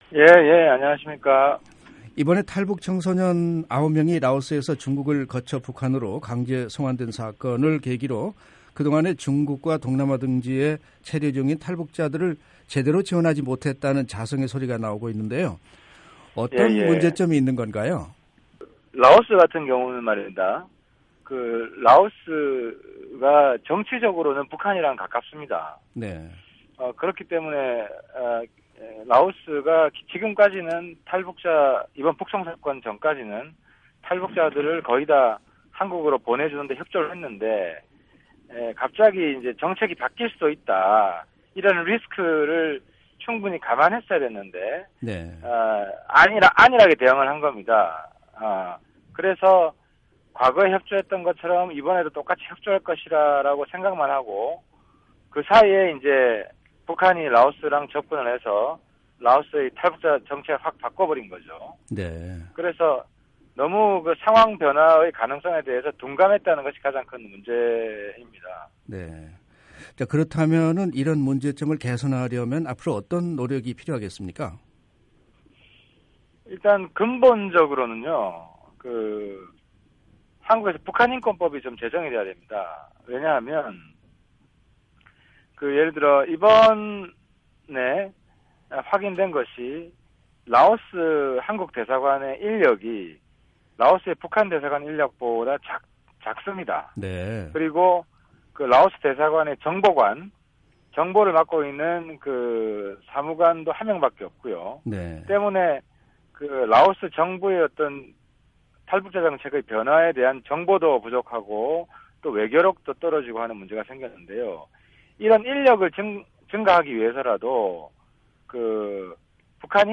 [인터뷰] 하태경 새누리당 의원